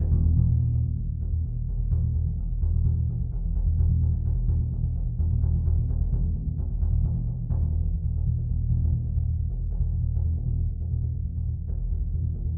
bassdrum_roll_mf.wav